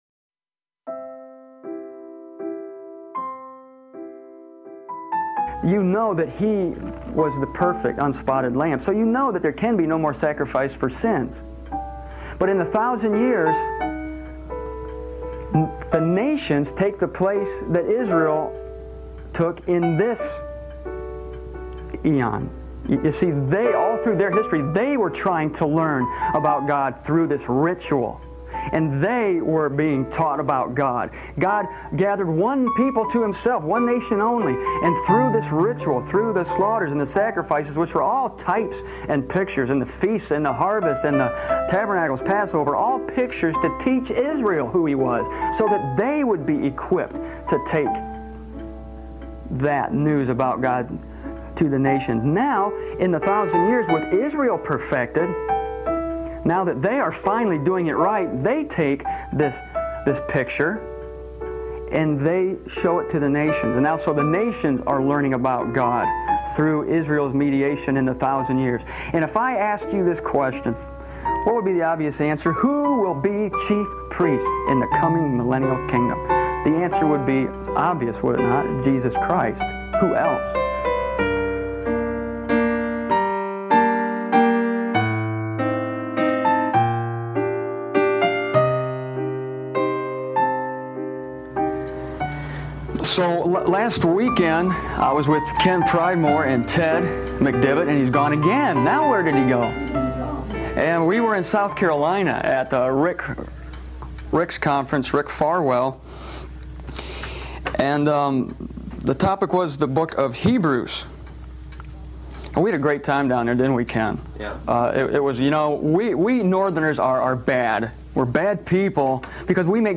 recorded before a small group of believers in Almont, Michigan